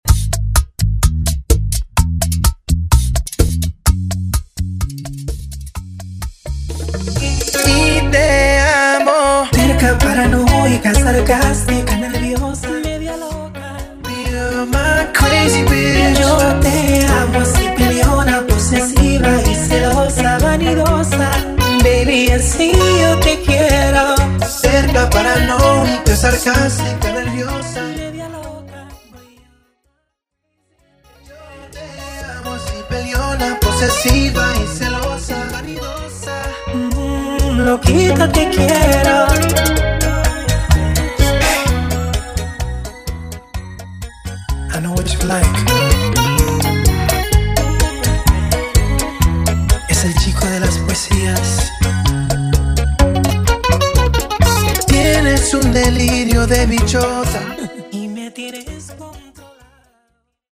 a talented Latin music remixer from Ecuador